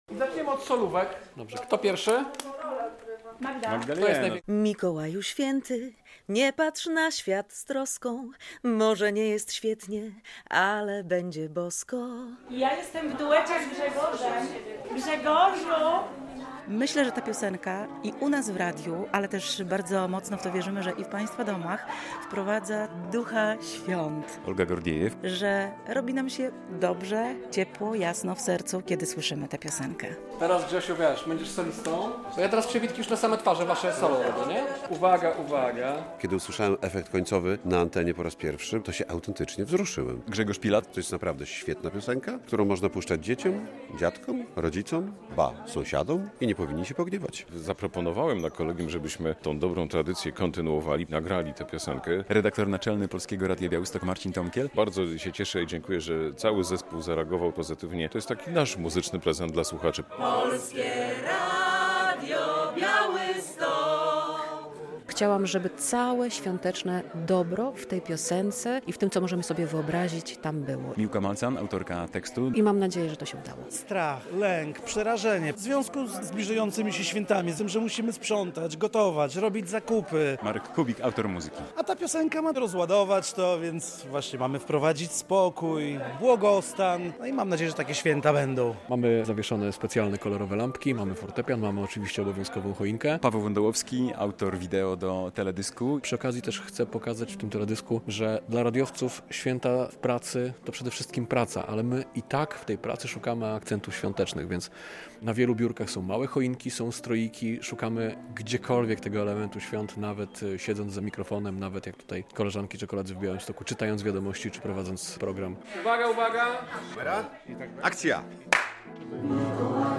Wkrótce premiera teledysku do świątecznej piosenki Polskiego Radia Białystok - relacja